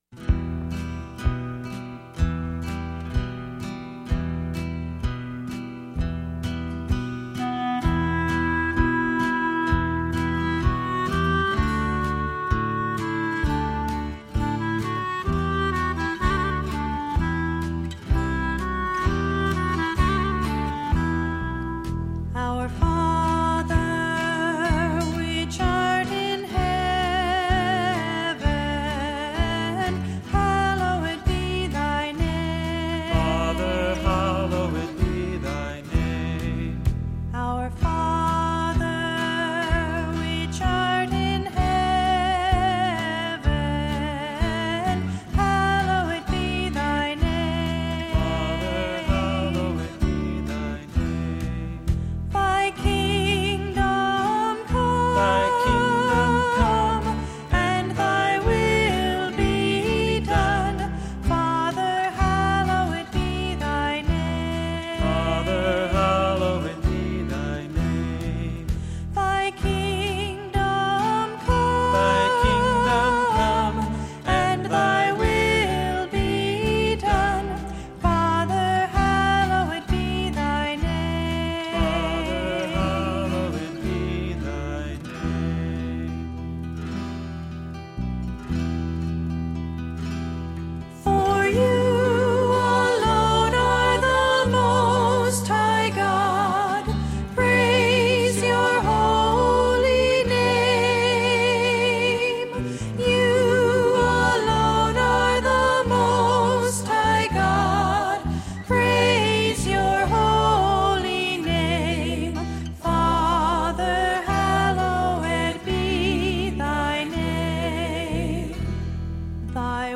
Piano
Electric & acoustic guitars
Bass guitar
Percussion
Trumpet
English horn, saxophone and clarinet
Synthesizer
Background vocals